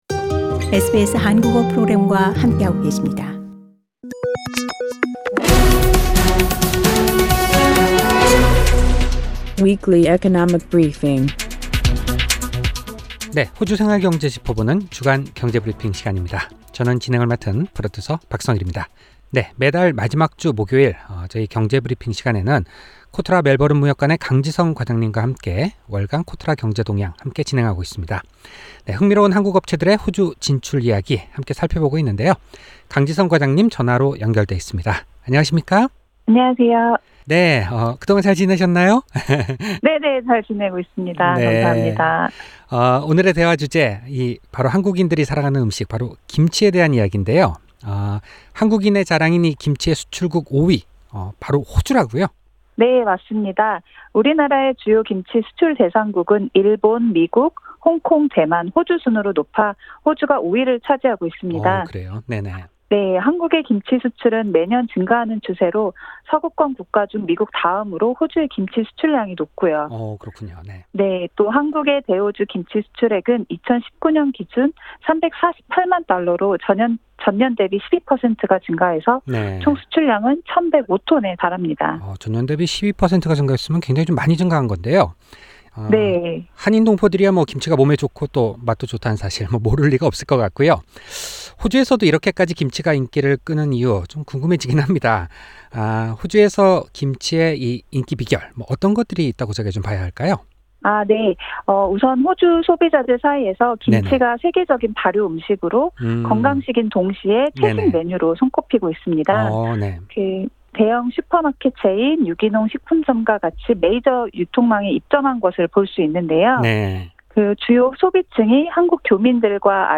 오늘도 전화로 연결되어 있습니다.